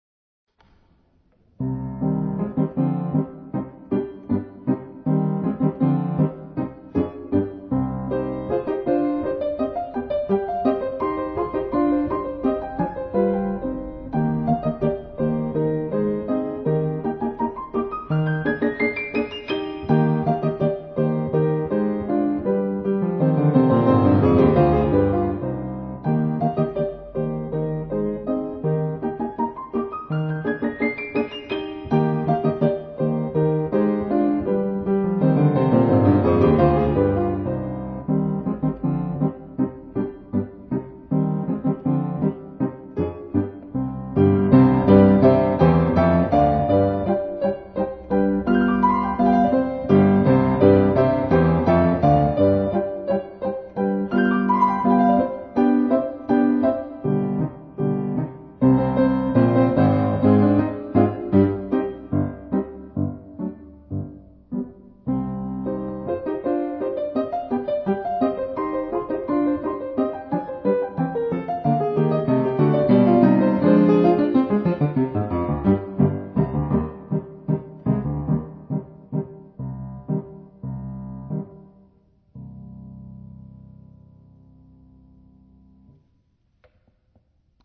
ピアノコンサート２
自宅のGPによる演奏録音 　　 デジピによる演奏録音